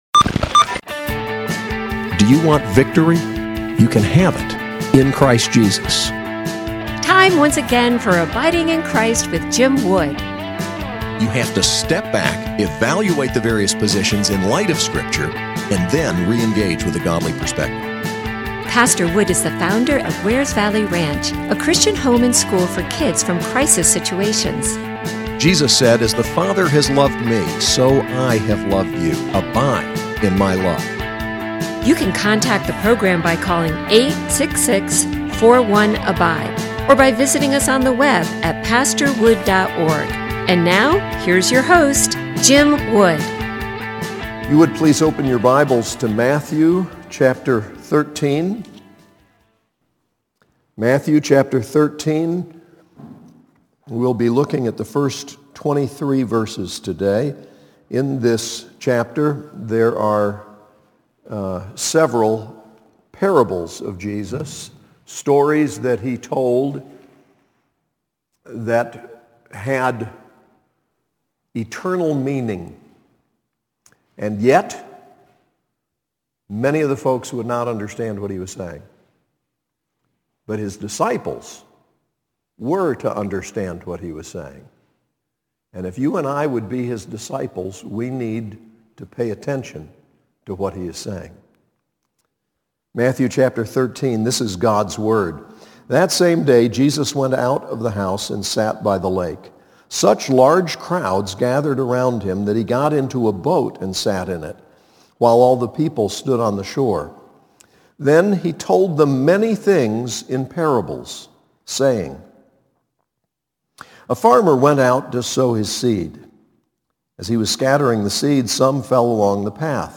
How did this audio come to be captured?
SAS Chapel: Matthew 13:1-23